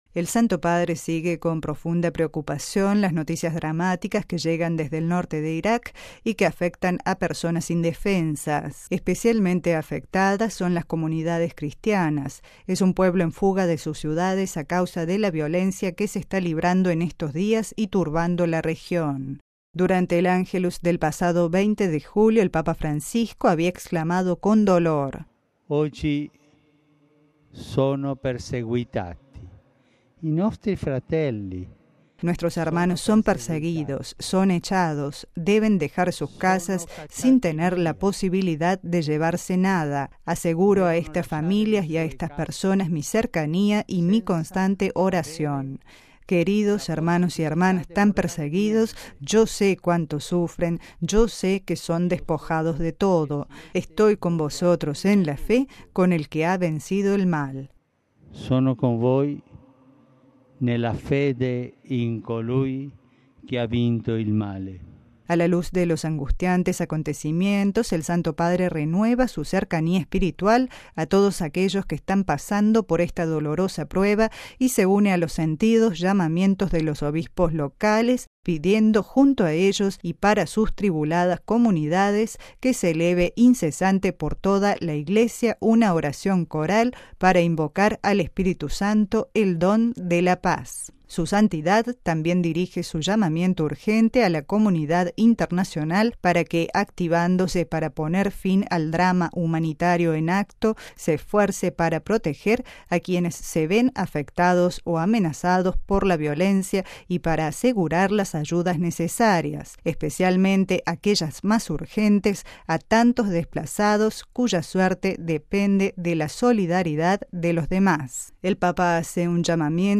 Declaración del P. Lombardi en nombre del Santo Padre sobre la situación de los cristianos en Irak